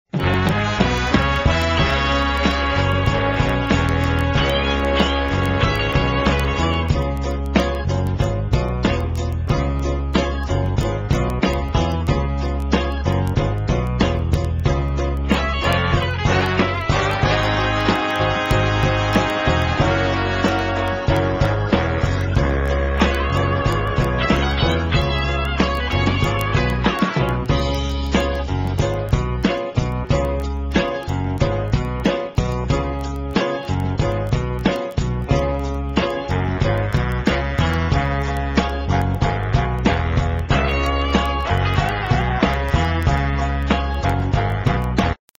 NOTE: Background Tracks 9 Thru 16